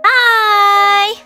Worms speechbanks
Yessir.wav